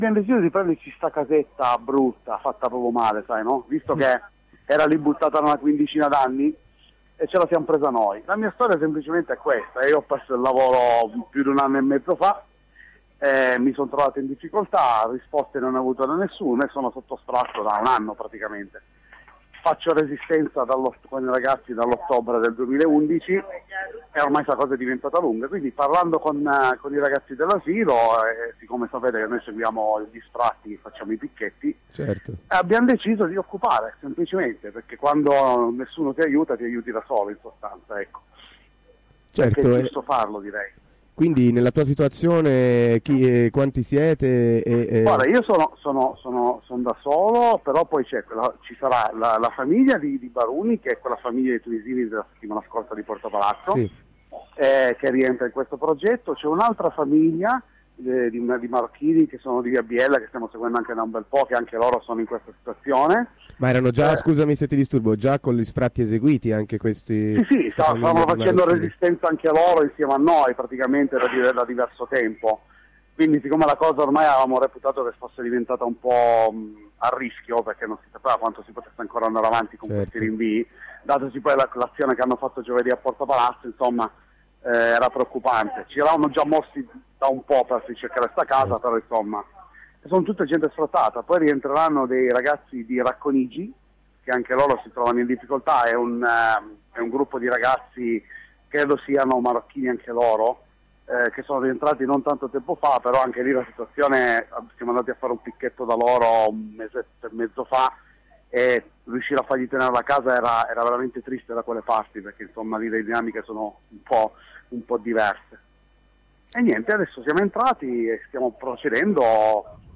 occupazione_corsonovara9.mp3